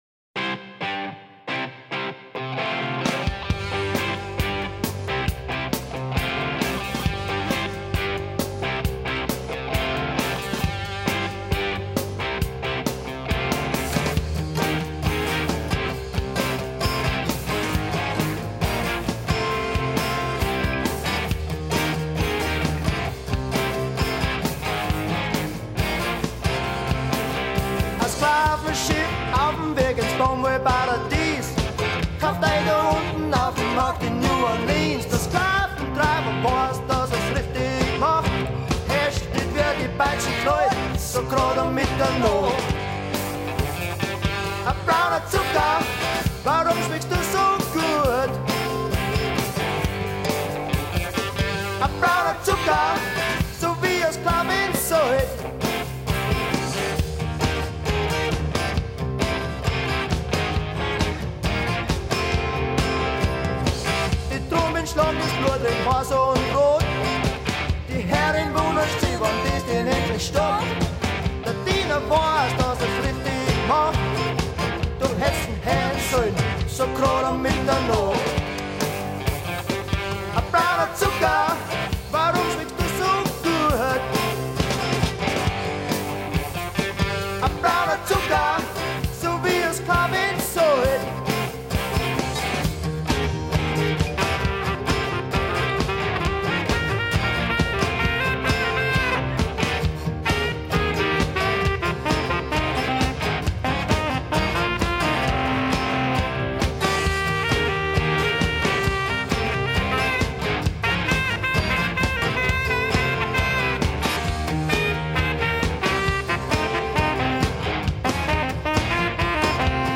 Rock'n'roll - das tut wohl
es im Fiawabrunner Dialekt zu singen.